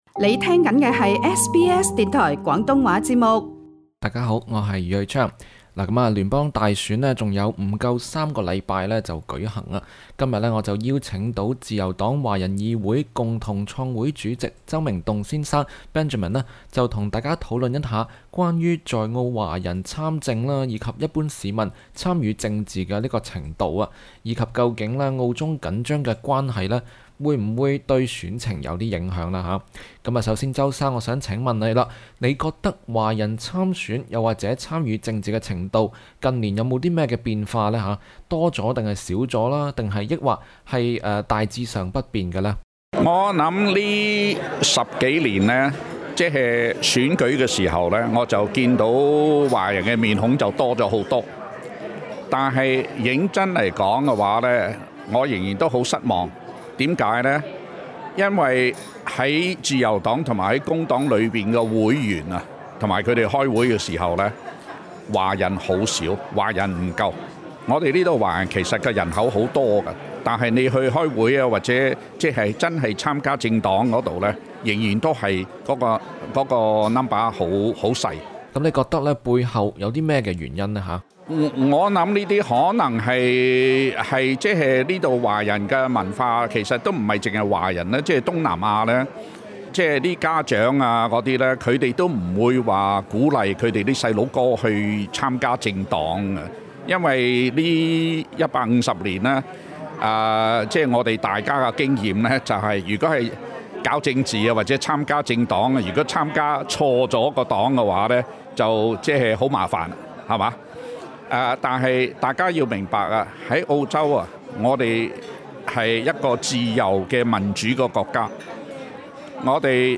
SBS广东话播客